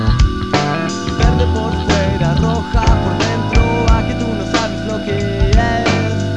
Su estilo mezcla elementos del Soul, Hip-Hop y el Funk.
guitarra, secuencias y voz
bajo y voz
raps y scratch